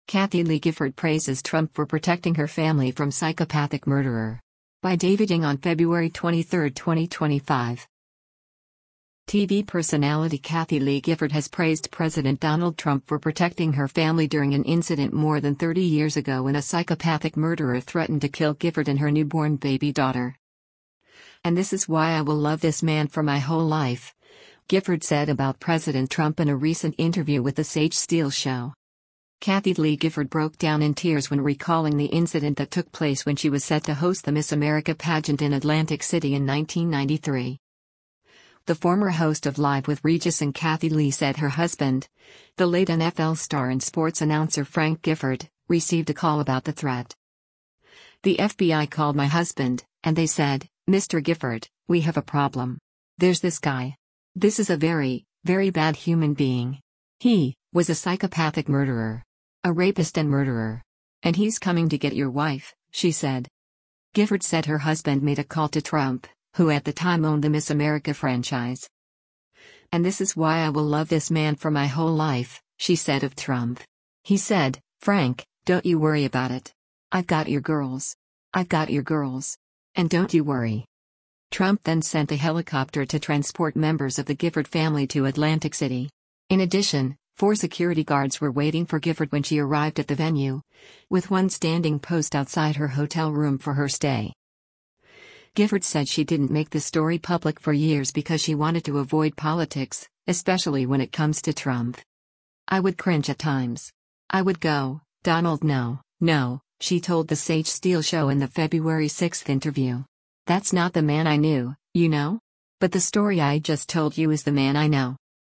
Kathie Lee Gifford broke down in tears when recalling the incident that took place when she was set to host the Miss America Pageant in Atlantic City in 1993.